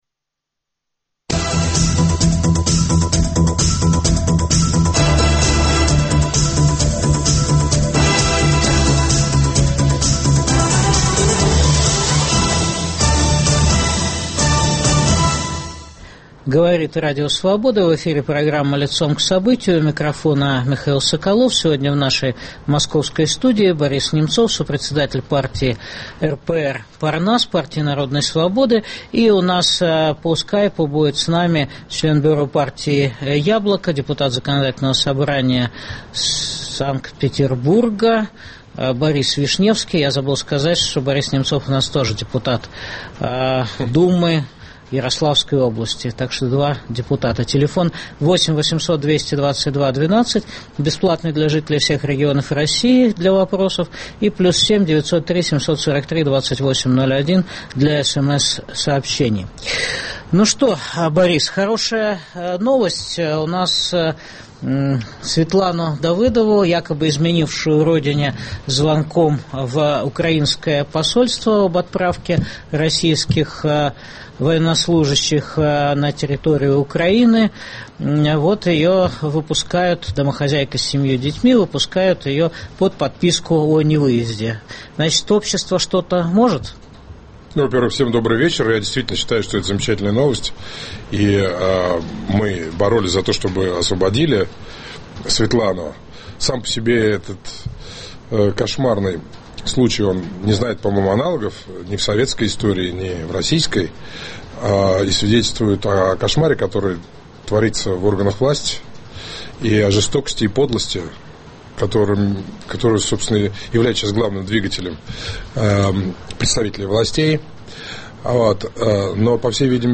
Как проводить Антикризисный марш против войны России на Украине 1 марта 2015 г. Обсуждают политики Борис Немцов (ПАРНАС) и Борис Вишневский ("Яблоко").